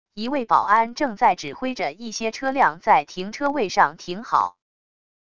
一位保安正在指挥着一些车辆在停车位上停好wav音频